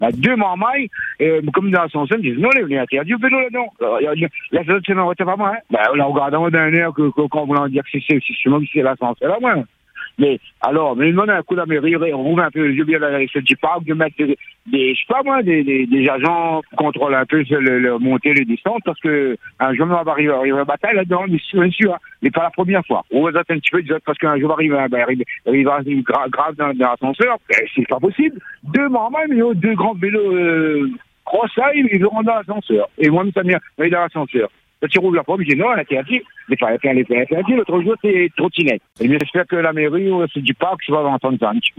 Selon un témoignage recueilli sur l’antenne, certains jeunes utiliseraient la cabine avec leurs vélos, la monopolisant au détriment des autres personnes souhaitant se déplacer.